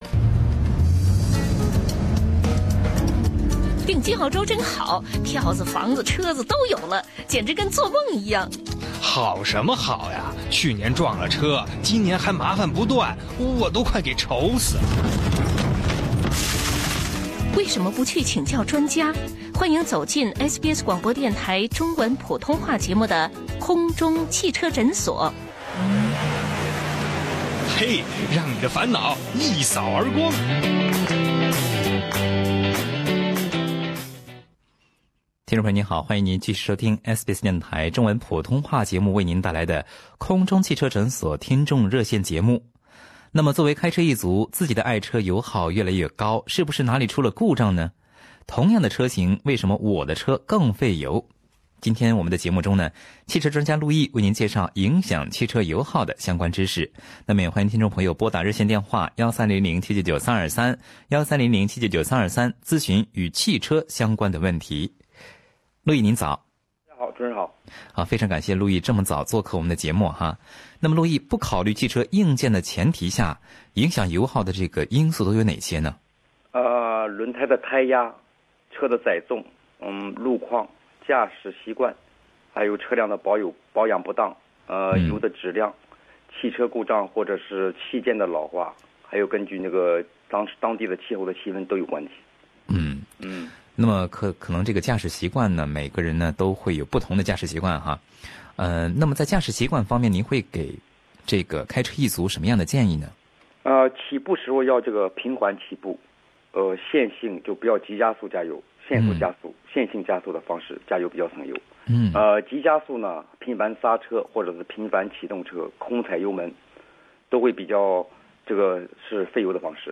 不少听众也对汽车问题进行了热线咨询，包括柴油车加油起泡加油困难问题，汽车刹车问题，汽车显示屏幕不工作等问题。